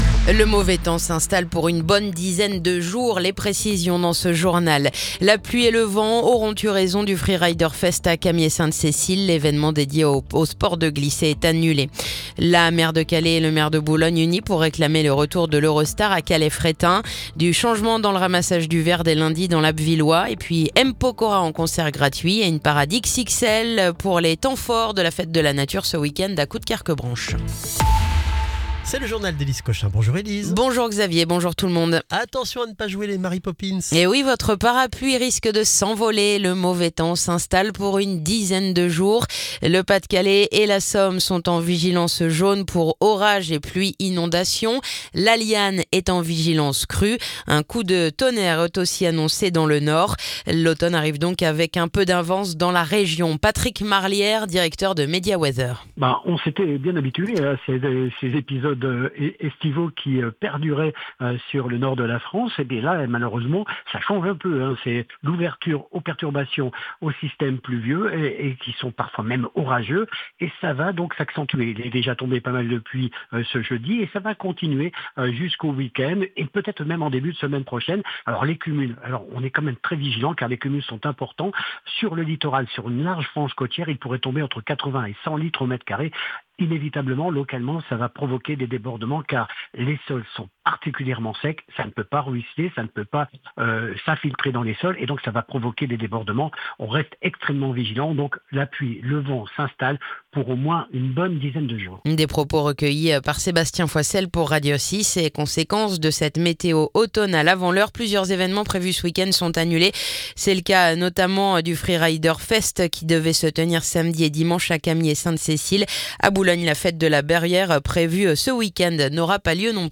Le journal du vendredi 12 septembre